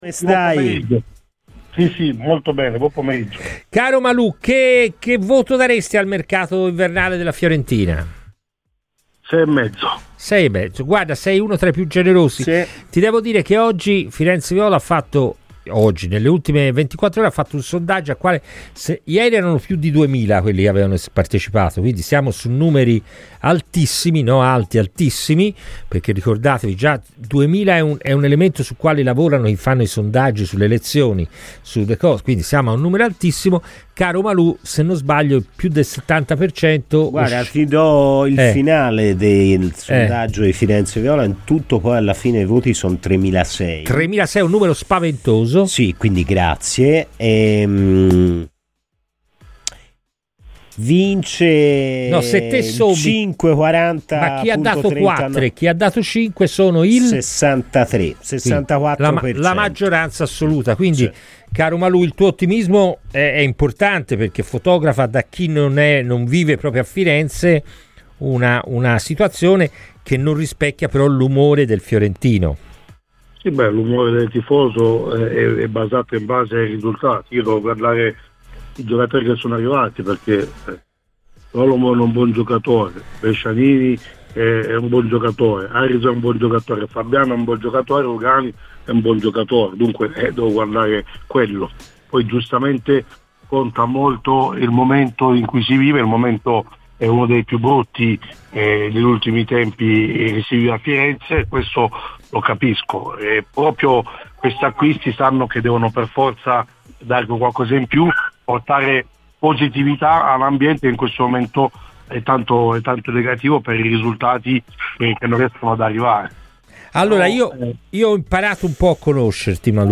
Io partirei con Rugani-Comuzzo come coppia" Ascolta il podcast per l'intervista completa.